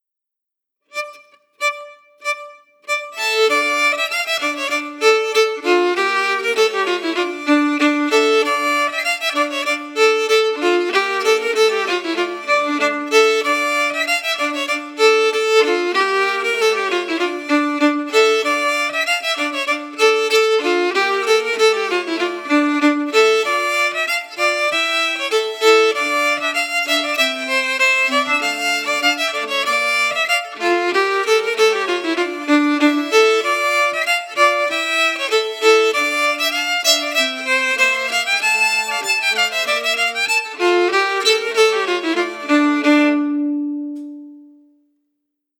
Key: D-minor
Form: Scottish Reel Song
MP3: Melody emphasis